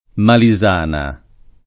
Malisana [ mali @# na ]